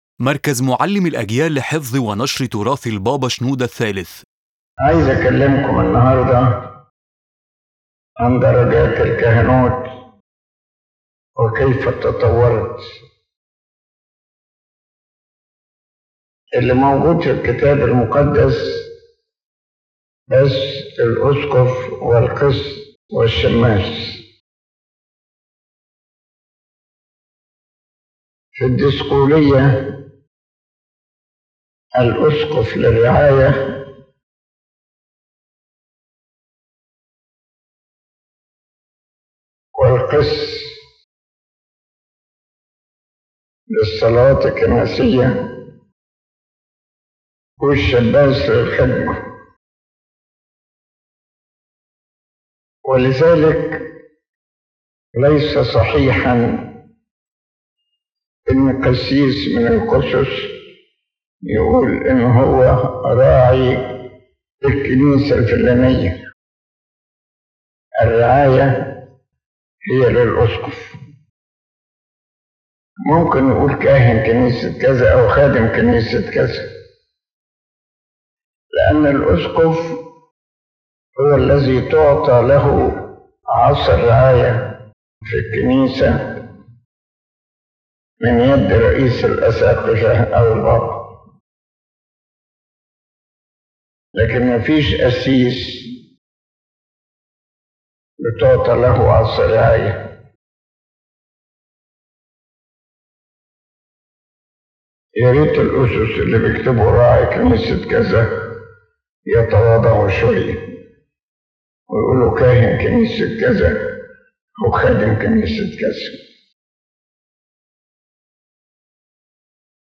explains in this lecture the degrees of priesthood and their development in the Church since apostolic times, clarifying the role and responsibility of each rank in service, and emphasizing the necessity of full dedication to God’s work in priestly life.